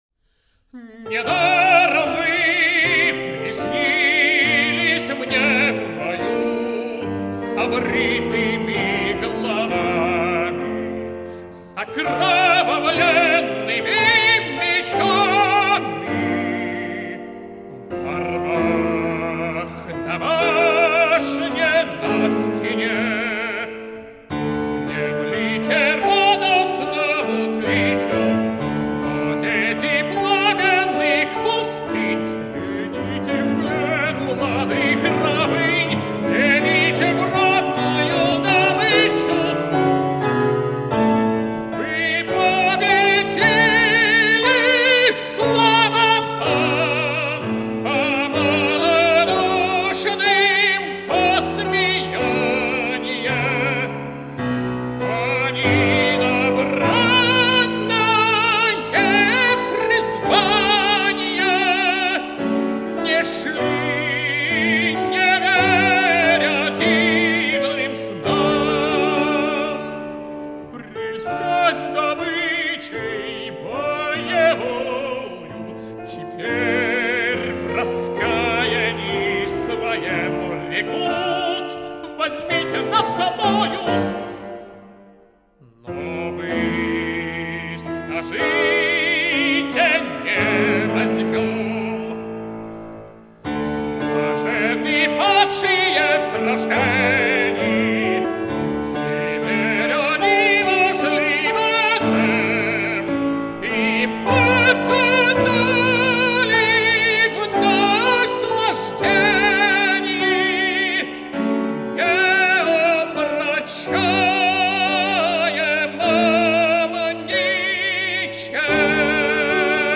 tenor
piano